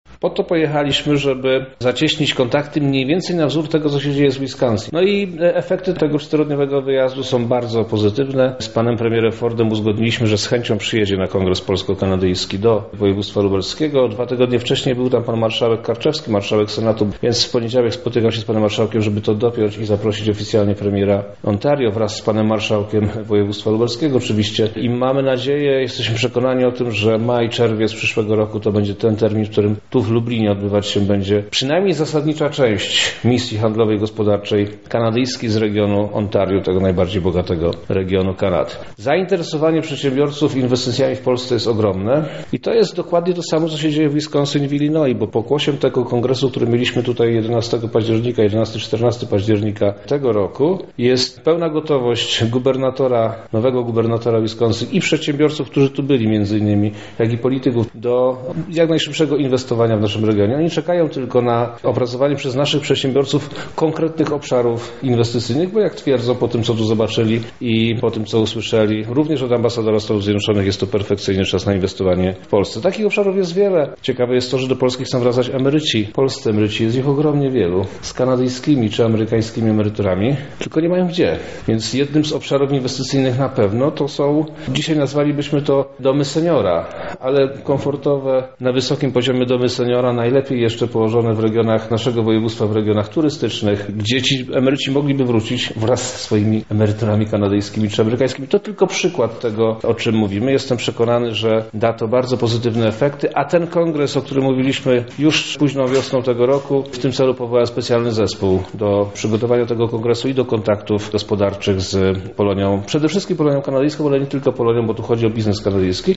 O tym jaki był cel podróży i czy Amerykanie są zainteresowani inwestowaniem w Polsce mówi sam wojewoda: